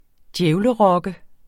Udtale [ ˈdjεwlə- ]